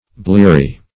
Bleary \Blear"y\, a.